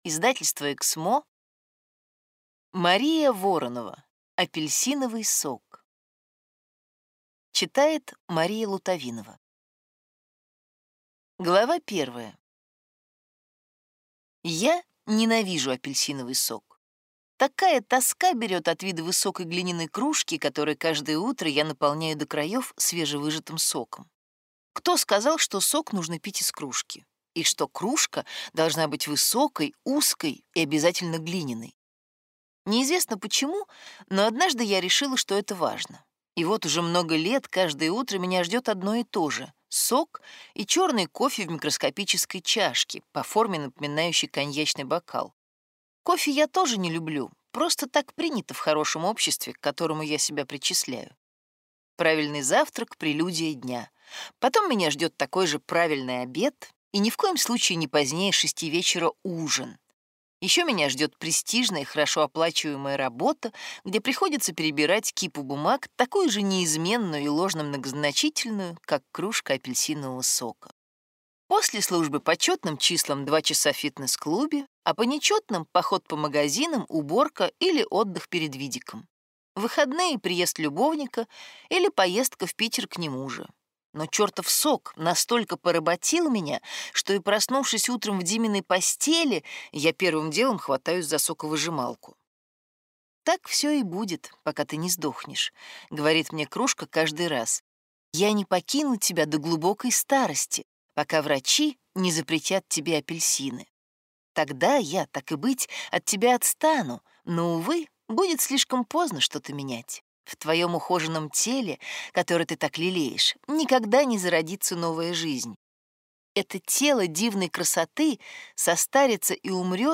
Аудиокнига Апельсиновый сок | Библиотека аудиокниг